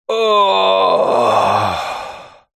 Звуки тяжелого вздоха
ох